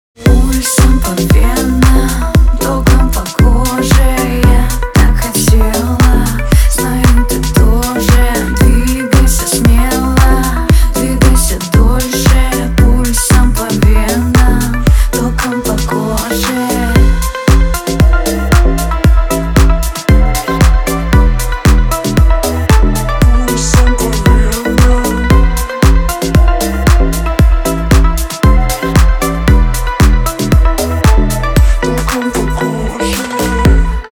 • Качество: 320, Stereo
deep house
чувственные